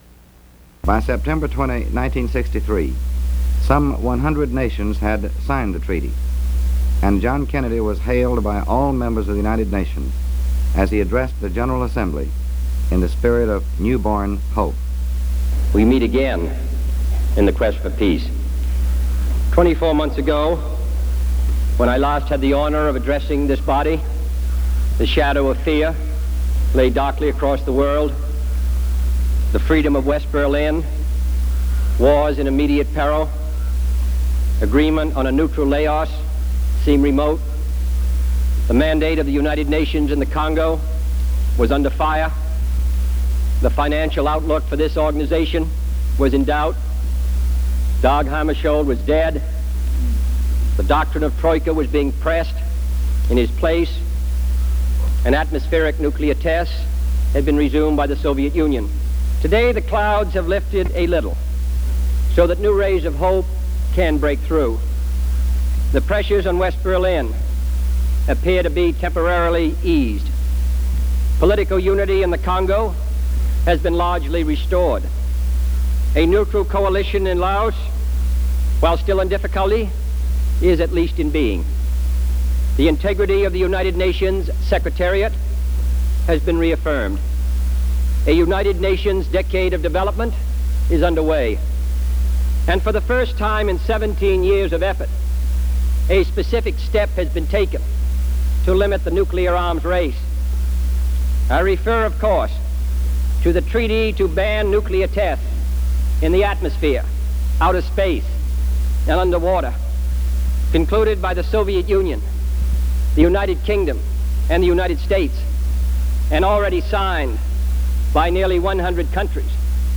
JFK address to the United Nations Sept. 20, 1963